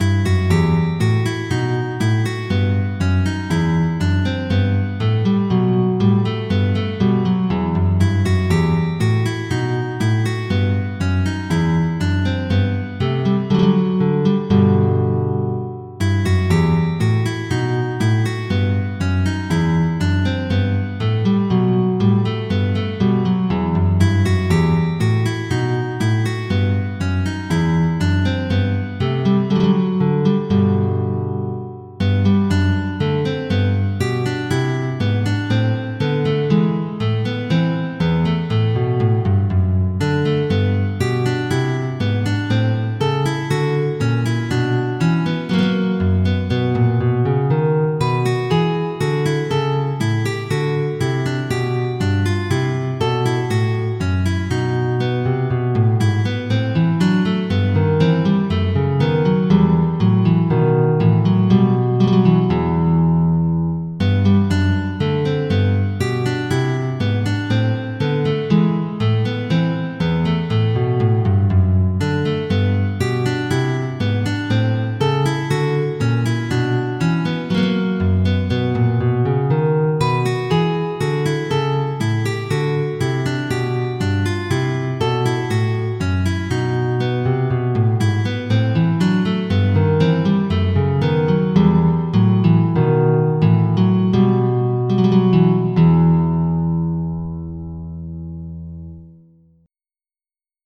MIDI Music File
bourree.mp3